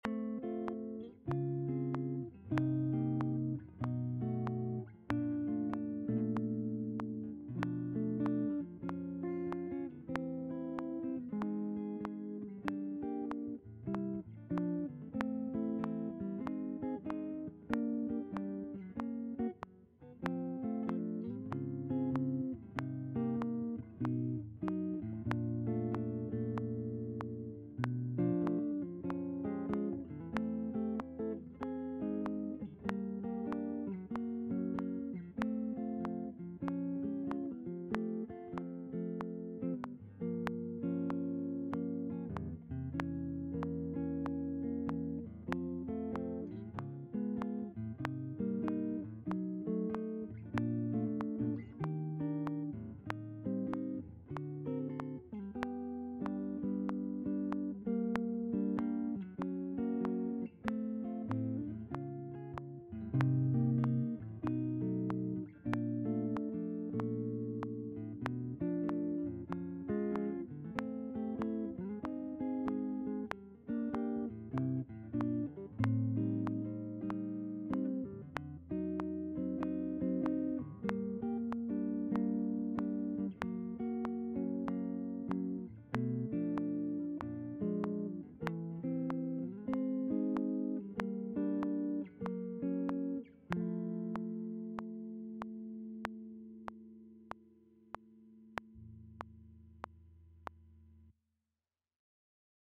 in F at 95 BPM